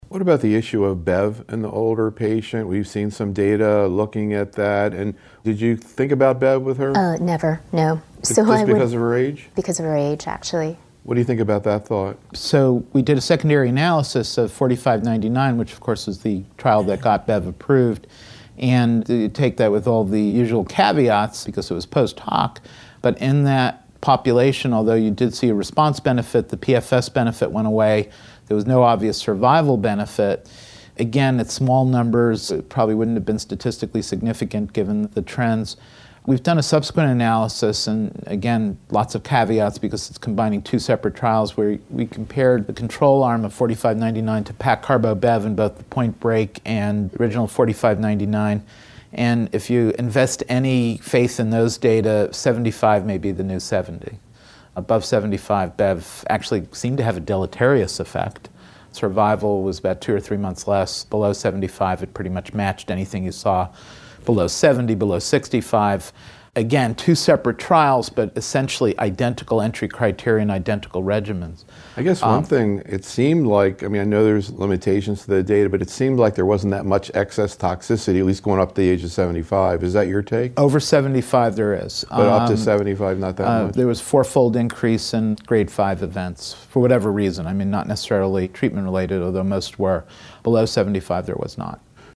In these audio proceedings from a closed Think Tank meeting held in January 2015, the invited faculty discuss and debate some of the key clinical management issues in lung cancer as well as promising research strategies in this area. The roundtable discussion focused on key presentations and papers and actual cases managed in the practices of the faculty in which these data sets factored into their decision-making.